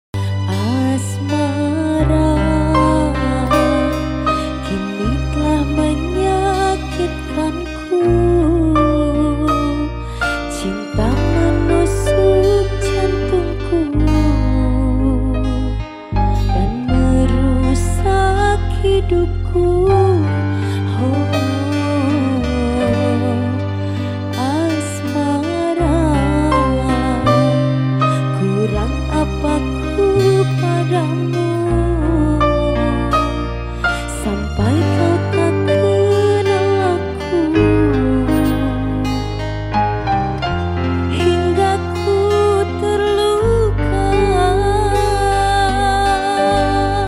LIVE ACOUSTIC COVER
Gitar Bass
Keyboard